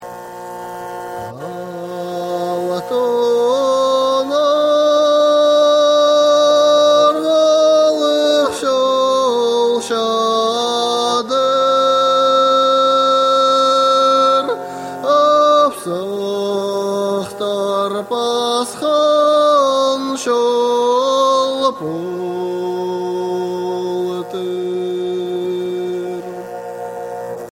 ....... song, Khaas people
voice (khai in kharygha style), yykh (fiddel)
chatkhan (wooden box zither)